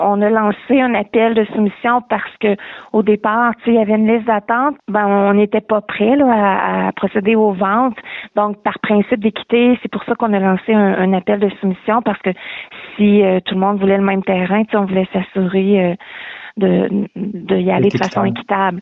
La mairesse a expliqué pourquoi la Ville a procédé ainsi.